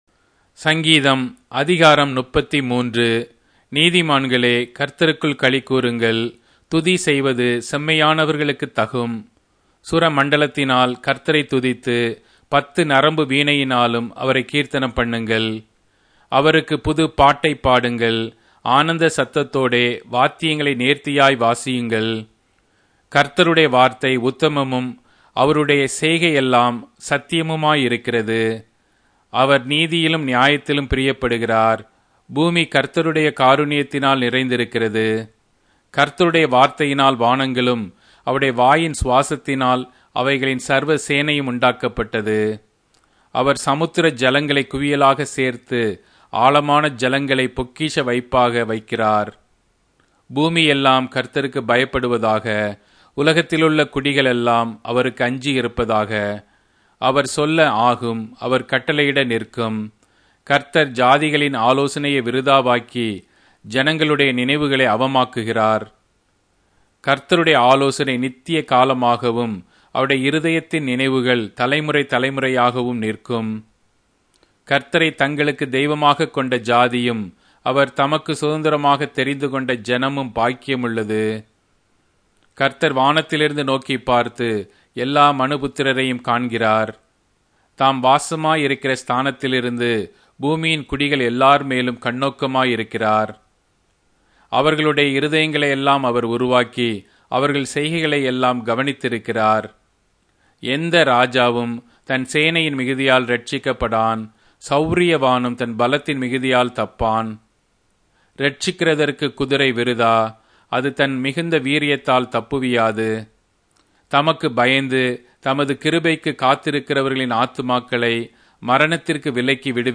Tamil Audio Bible - Psalms 83 in Bnv bible version